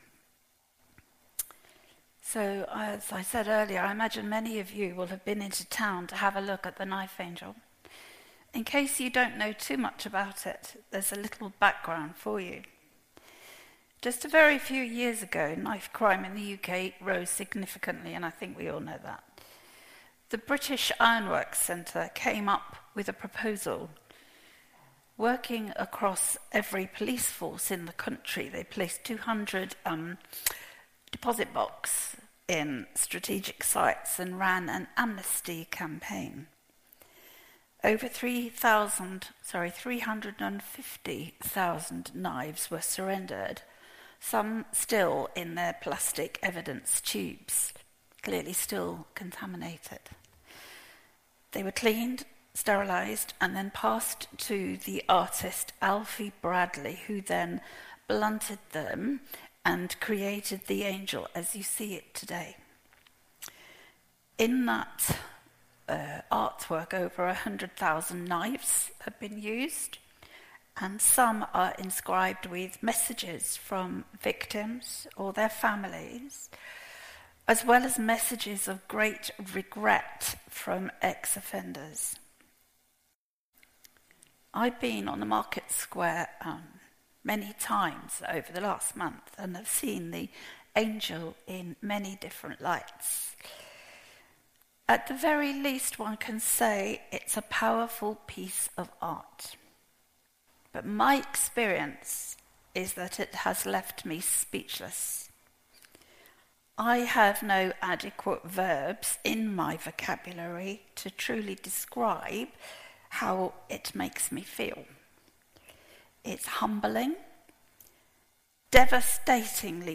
An audio version of the sermon is also available.
09-22-sermon.mp3